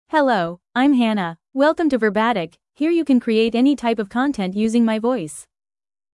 Hannah — Female English (United States) AI Voice | TTS, Voice Cloning & Video | Verbatik AI
FemaleEnglish (United States)
Hannah is a female AI voice for English (United States).
Voice sample
Female
Hannah delivers clear pronunciation with authentic United States English intonation, making your content sound professionally produced.